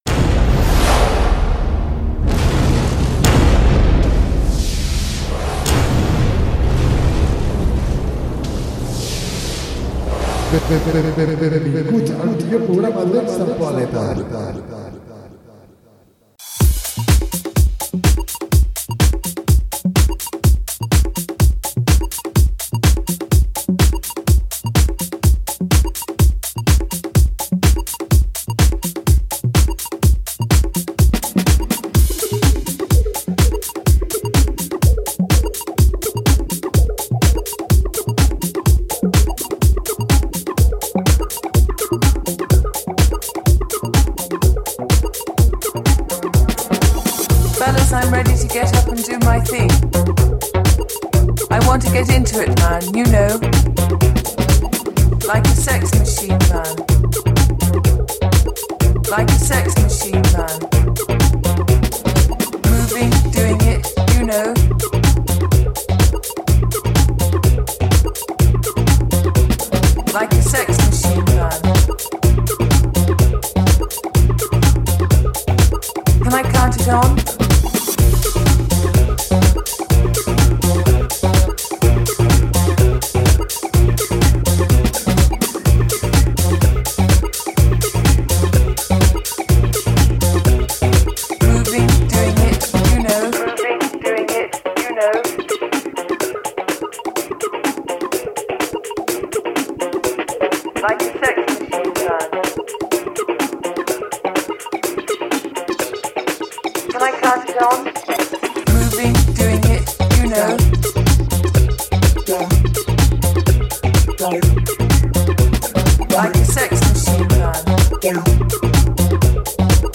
Funky House, Deep House, Tech House i Nu Disco